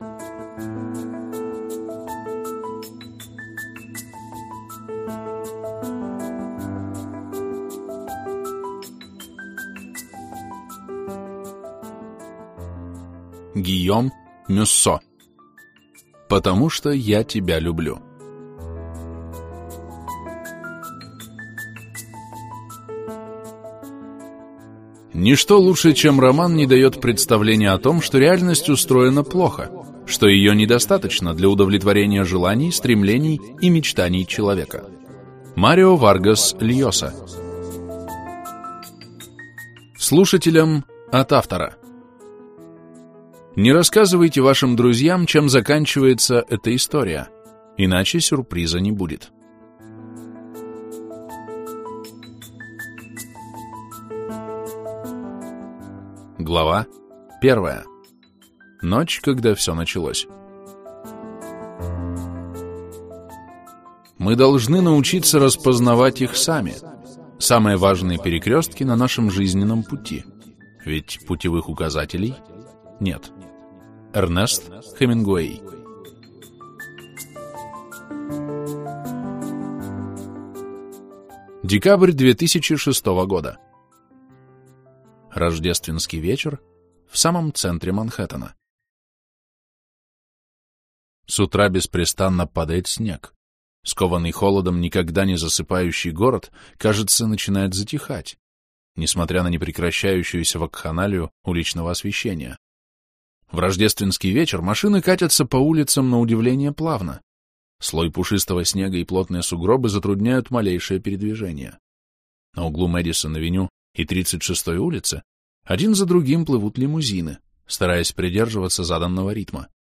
Аудиокнига Потому что я тебя люблю - купить, скачать и слушать онлайн | КнигоПоиск